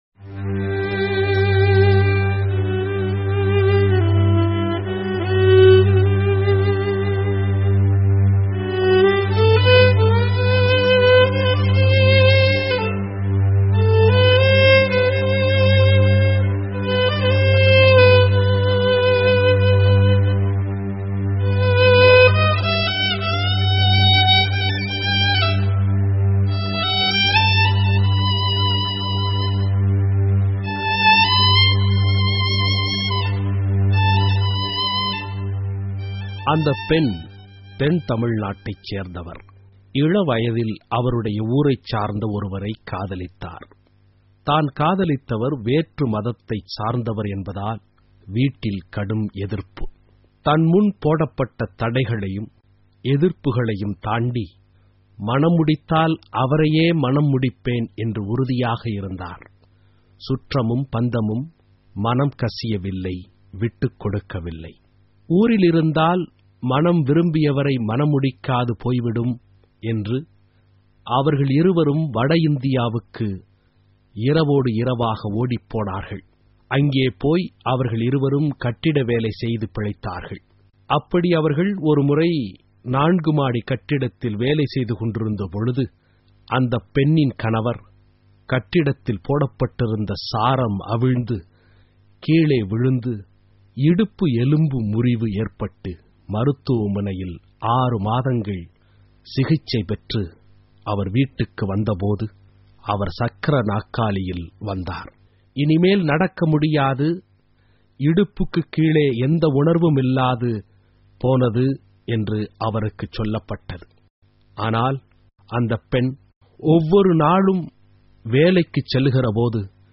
Tamil Homilies Lenten